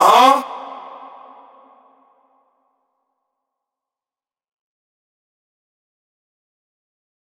DMV3_Vox 8.wav